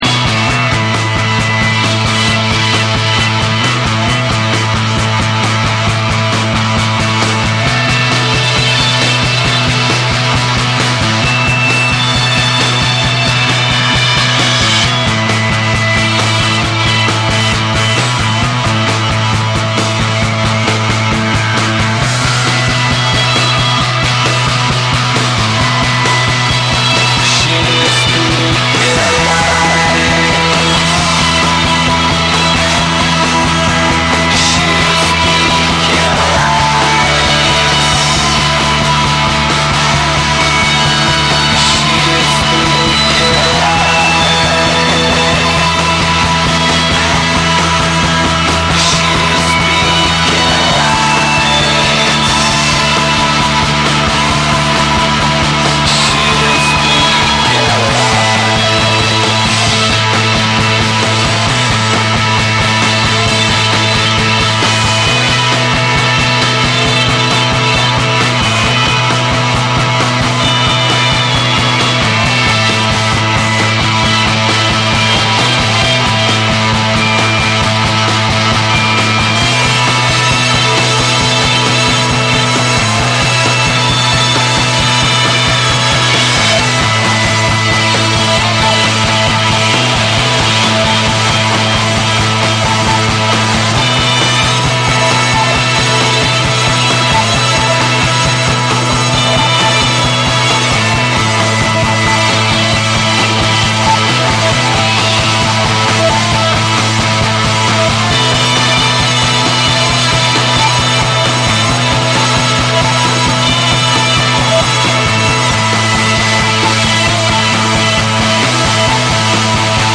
メリハリのある重心低めのブレイクビーツにサイケデリックな演奏が交わる
歪んだベースとノイジーなギター、電子音が交錯する、アグレッシヴでインダストリアルなエレクトロ・ロック
NEW WAVE & ROCK